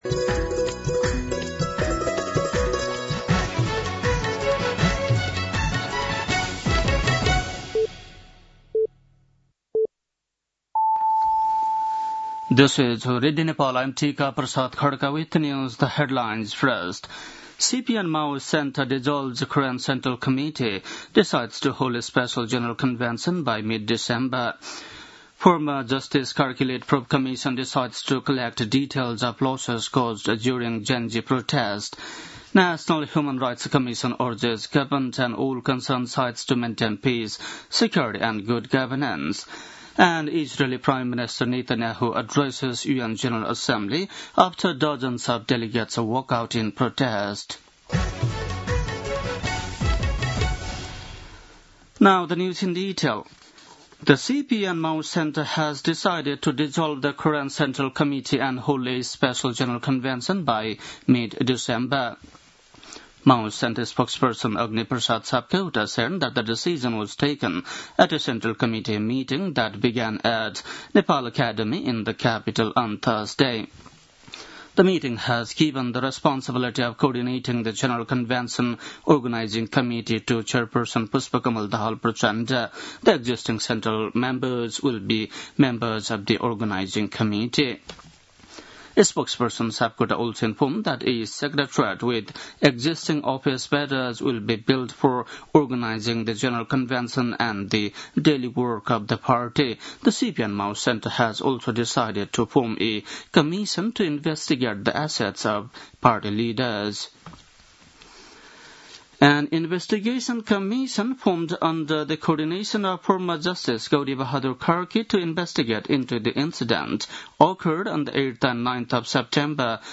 बेलुकी ८ बजेको अङ्ग्रेजी समाचार : १० असोज , २०८२